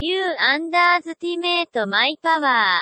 Tags: Star Wars japanese dub